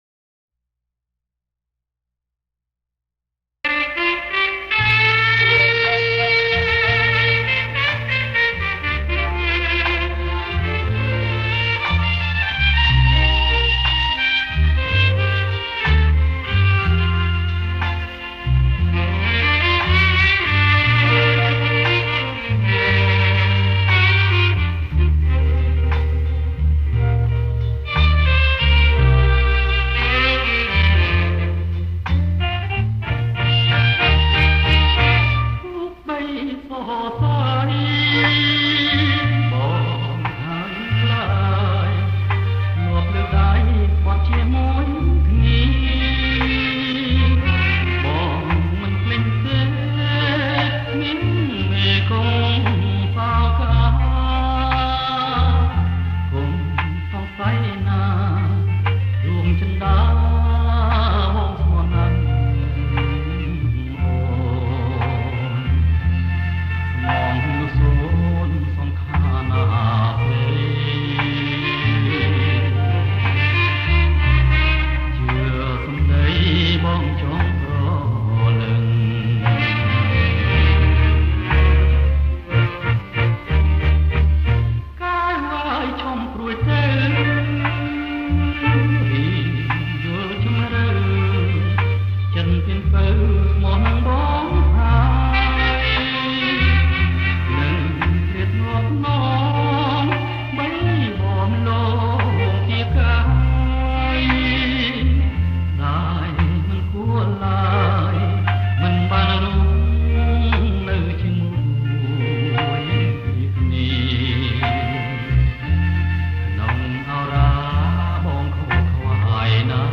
• ប្រគំជាចង្វាក់ Slow Rock